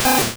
Cri de Rattata dans Pokémon Rouge et Bleu.